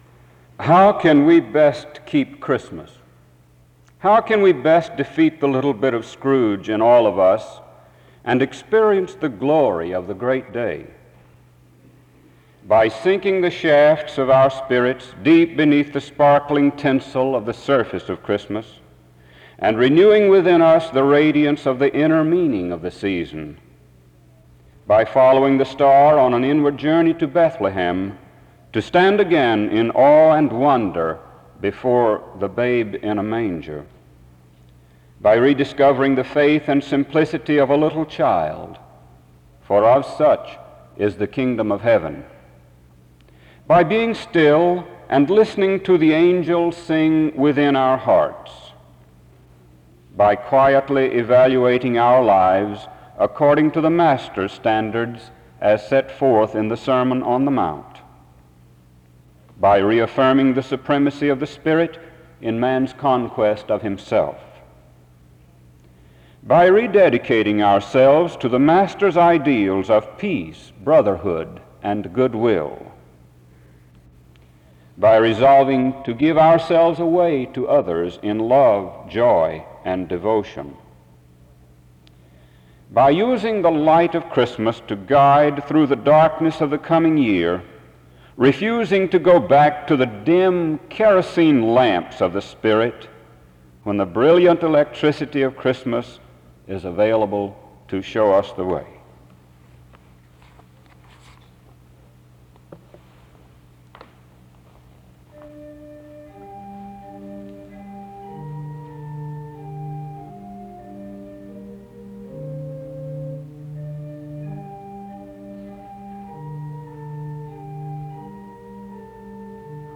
SEBTS Chapel
Music plays from 1:45-3:22.
Closing music is played from 16:41-18:02.
SEBTS Chapel and Special Event Recordings SEBTS Chapel and Special Event Recordings